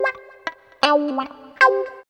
74 GTR 3  -R.wav